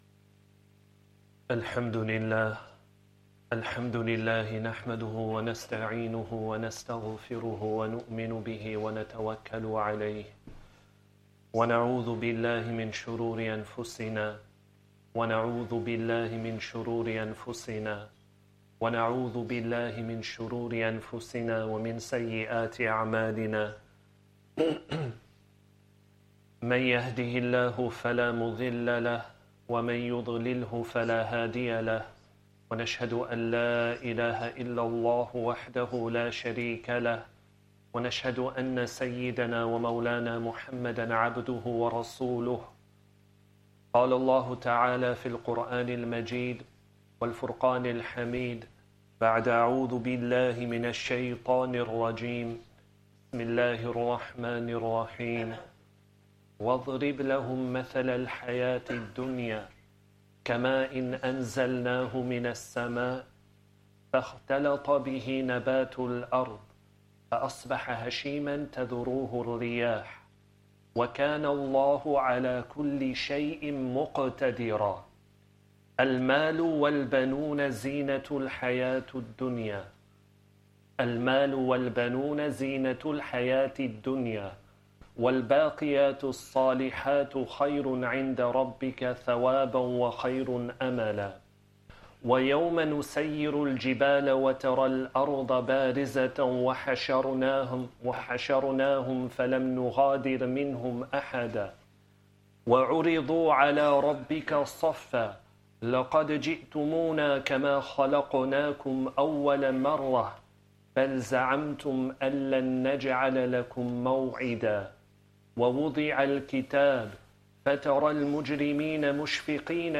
Jummah Talk and 1st Khutbah